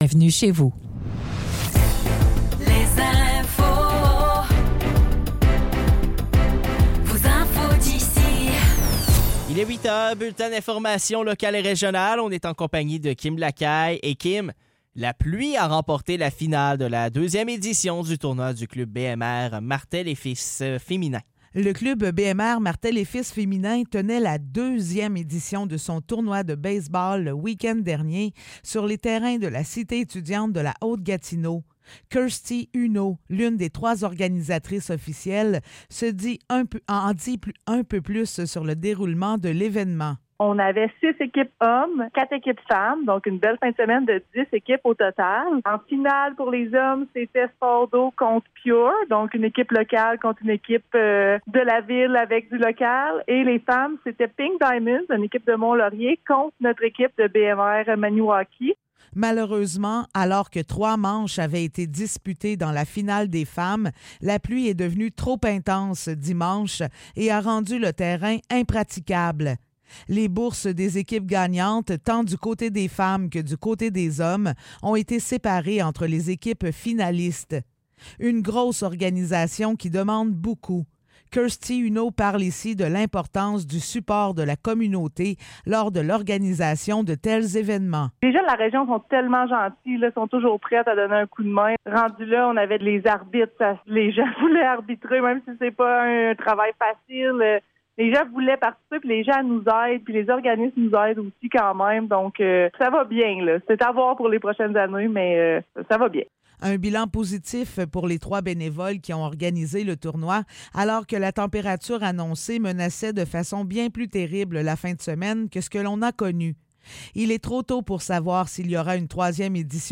Nouvelles locales - 25 juin 2024 - 8 h